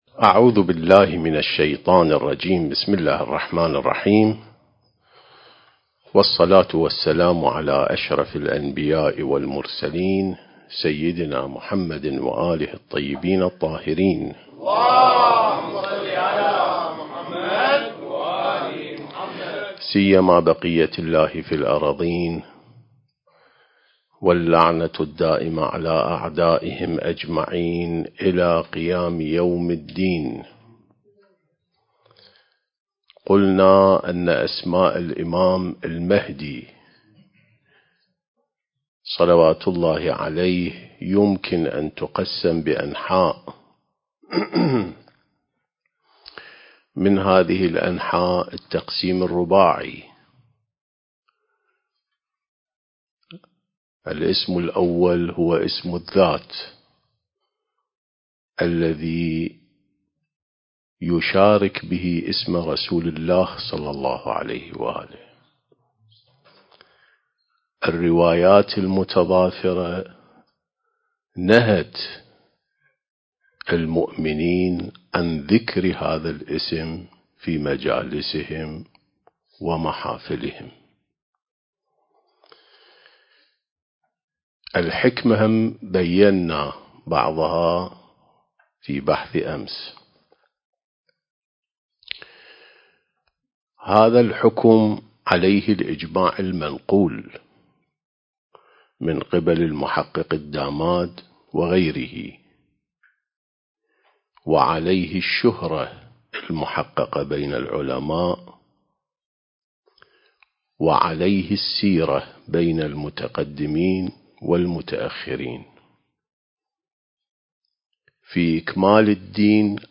سلسلة محاضرات عين السماء ونهج الأنبياء (4) التاريخ: 1443 للهجرة